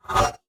Scifi Screen UI 1.wav